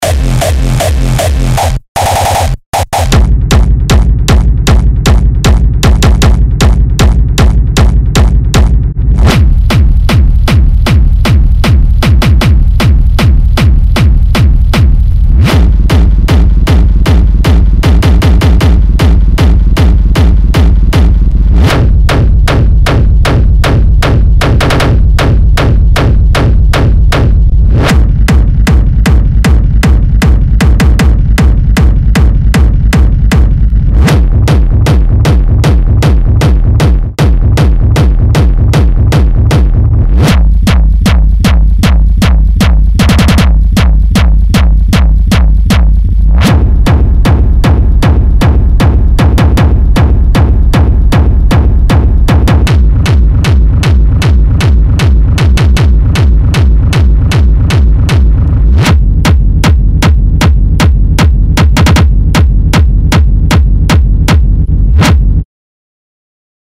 此免费素材包包含50 个硬核 Techno 震撼底鼓20 个 Hardstyle 底鼓，旨在提供最大的冲击力和低频冲击。
一款专业级工具包，专为在硬核电子乐、原始风格音乐及相关子流派中寻求紧凑、高冲击力底鼓设计的制作人而设计。